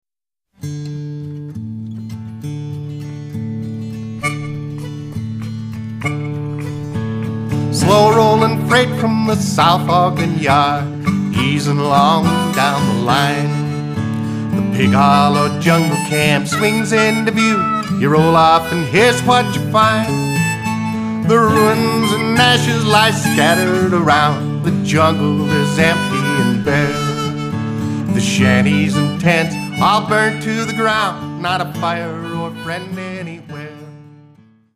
Harmonica